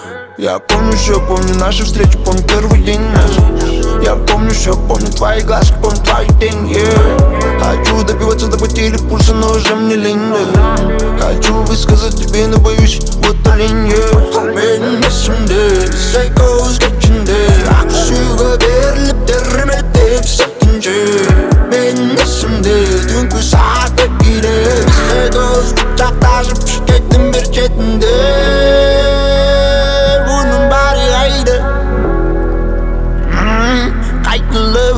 Жанр: Рэп и хип-хоп / Альтернатива / Русские
# Alternative Rap